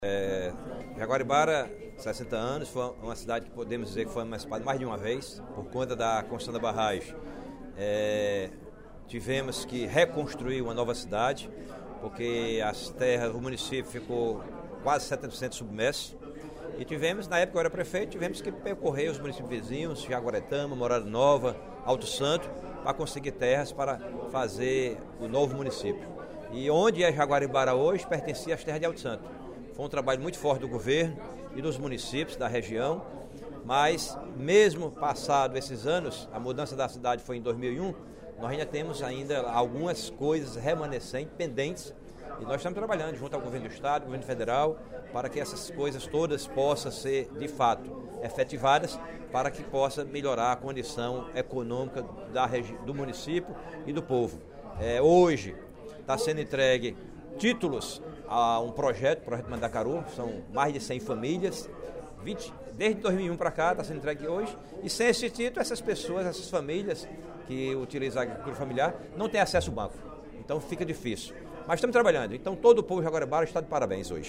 O deputado Antônio Granja (PDT) comemorou, no primeiro expediente da sessão plenária desta quinta-feira (09/03), os 60 anos de emancipação do município de Jaguaribara.